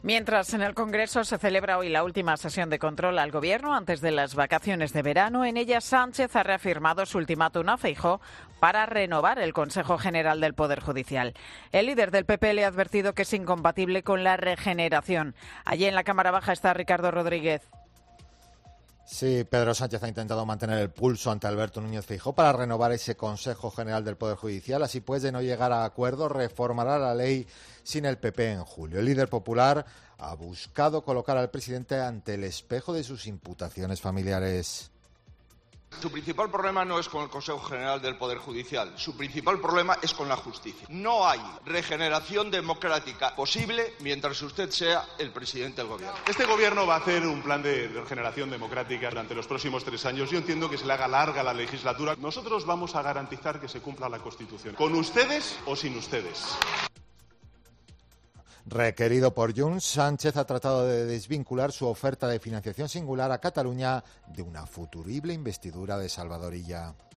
Nueva rifirrafe y bronca sesión de control al Gobierno en el Congreso de los Diputados
Feijóo ha provocado el aplauso de su bancada cuando ha afirmado: "señor Sánchez, no hay regeneración democrática en España posible mientras usted sea presidente del Gobierno".